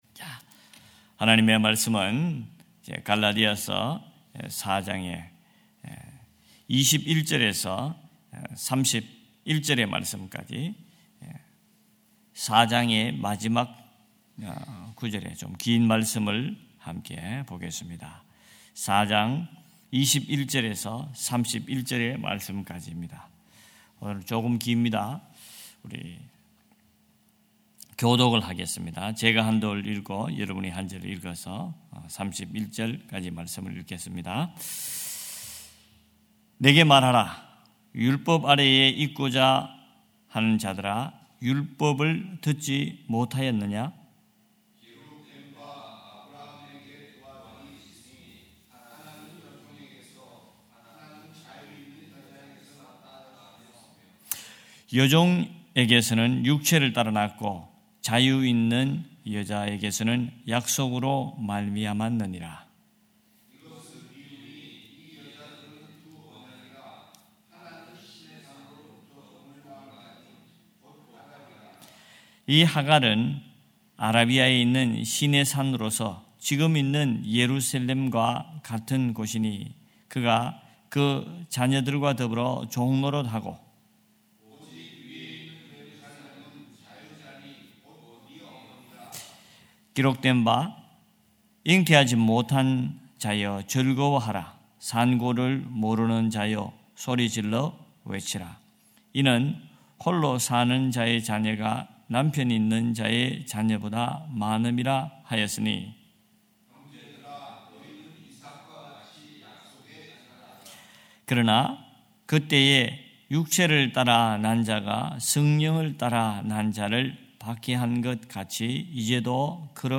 8.02.2025 새벽예배 갈라디아서 4장 21-31절